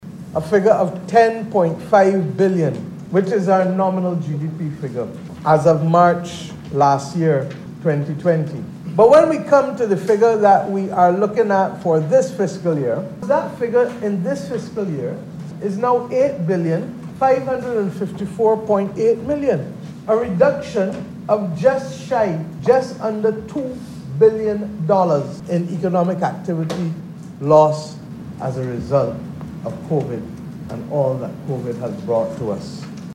Miss Mottley, who is the minister of finance, economic affairs and investment, was speaking as the estimates presentation continued in the lower chamber.